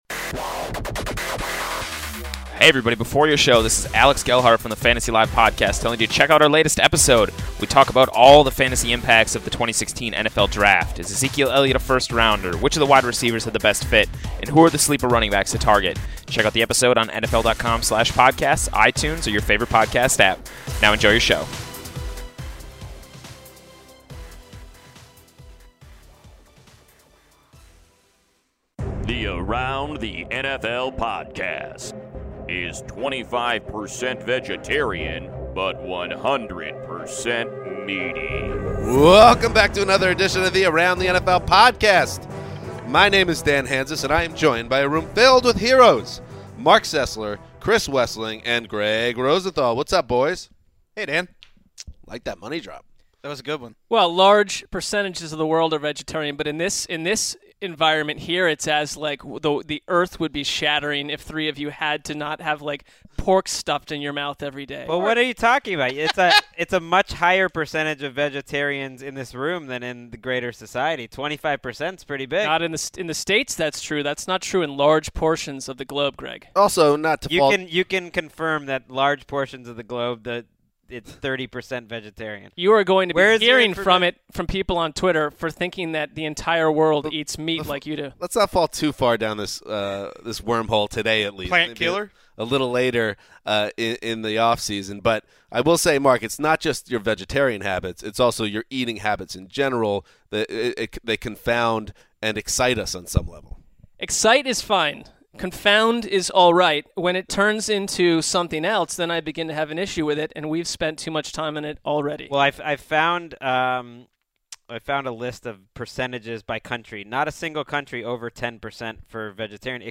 Highlights include when Paxton Lynch will start for the Denver Broncos, if Rex Ryan ignored the Bills’ needs in the draft and if the Colts are being overlooked. Plus, the heroes are joined by a special guest who wants to weigh in on the 2016 NFL Draft.